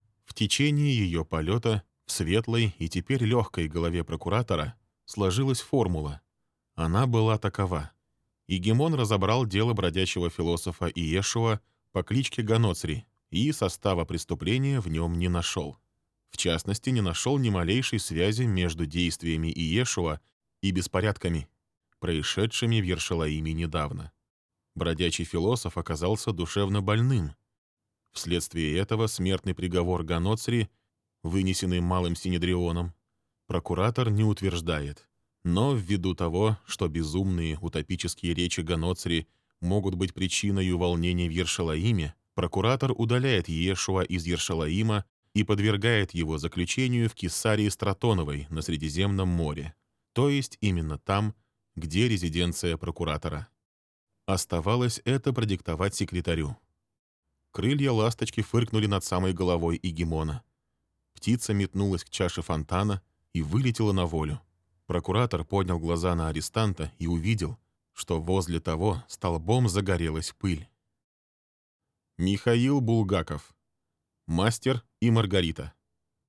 • Кабина + Focusrite 2i2 3rd gen + dbx 286s + Rode NTG3b • Качественные кабели, тихое помещение, ничего не шумит.